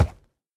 1.21.5 / assets / minecraft / sounds / mob / horse / wood3.ogg
wood3.ogg